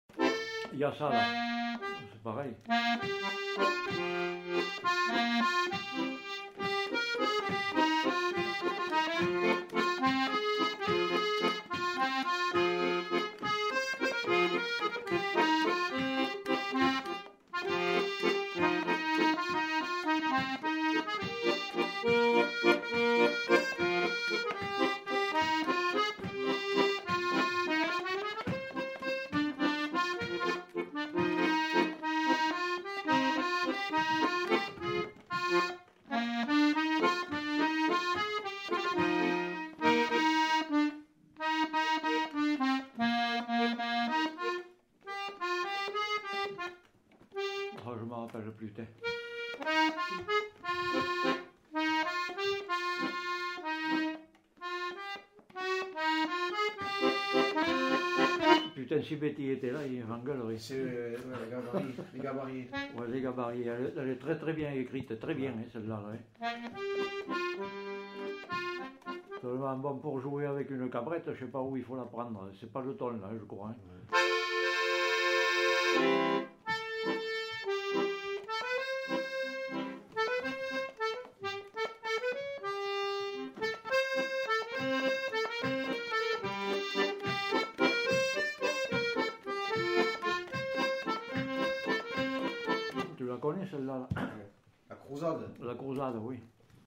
Aire culturelle : Quercy
Lieu : Bétaille
Genre : morceau instrumental
Instrument de musique : accordéon chromatique
Danse : valse